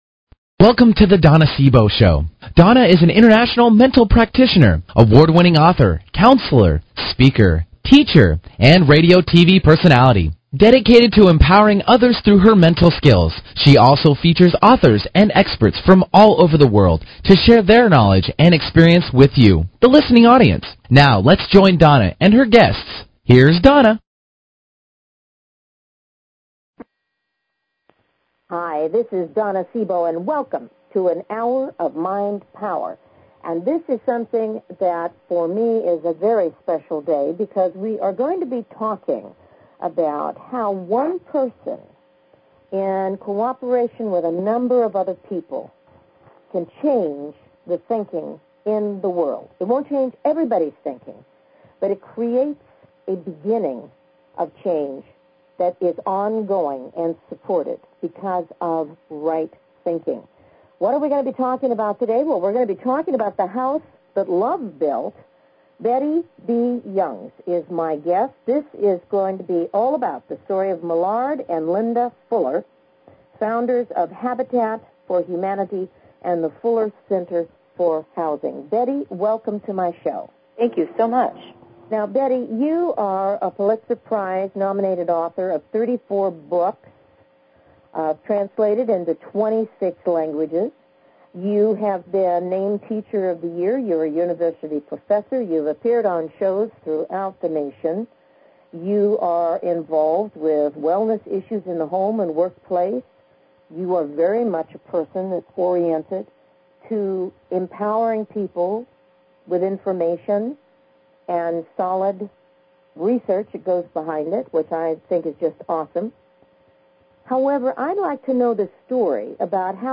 Talk Show Episode, Audio Podcast
Her interviews embody a golden voice that shines with passion, purpose, sincerity and humor.
Callers are welcome to call in for a live on air psychic reading during the second half hour of each show.